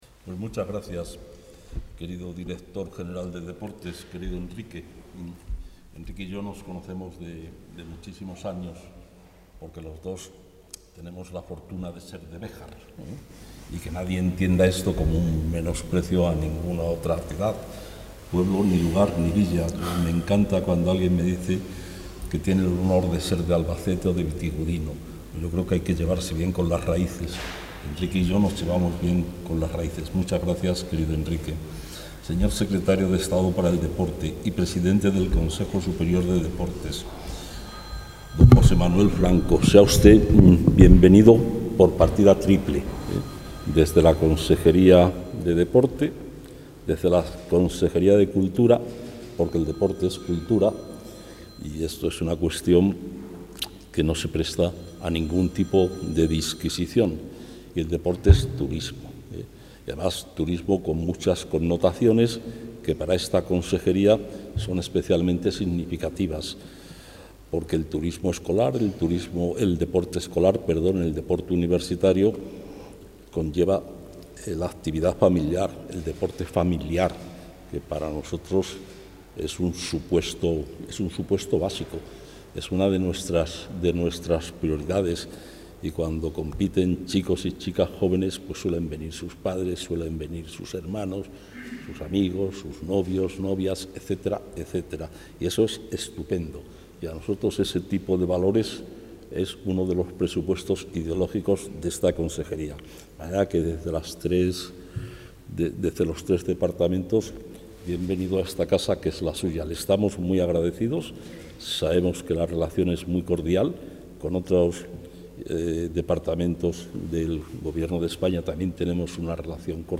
El consejero de Cultura, Turismo y Deporte, Gonzalo Santonja, ha presentado hoy en Valladolid los Campeonatos de España Universitarios 2023....